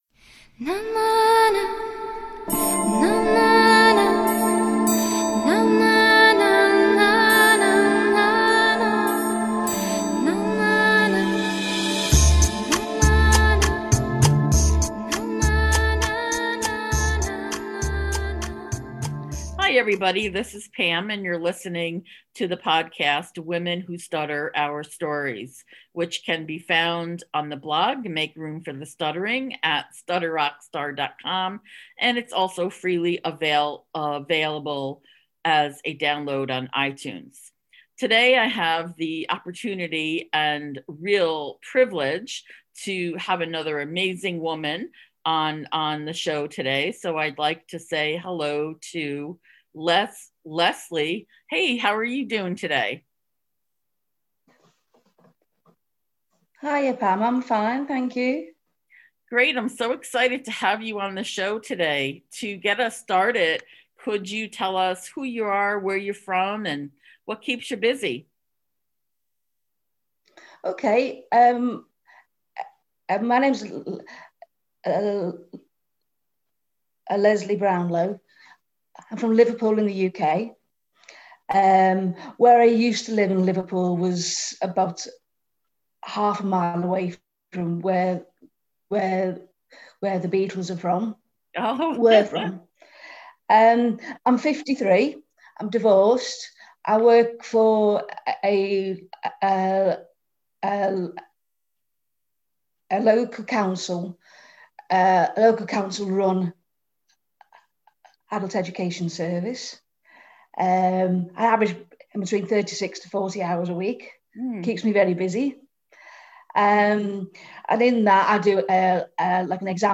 We also discussed the benefits of meeting others who stutter and how liberating that can be. This was a wonderful, inspiring conversation that could have go on for hours, but we were mindful of listener attention spans these days!